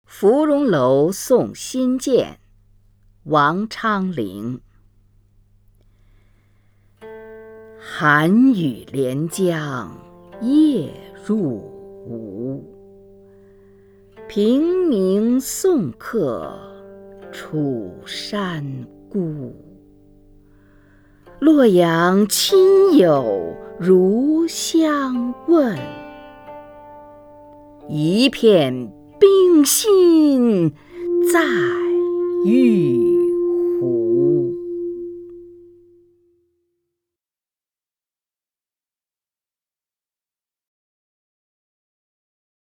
虹云朗诵：《芙蓉楼送辛渐》(（唐）王昌龄) （唐）王昌龄 名家朗诵欣赏虹云 语文PLUS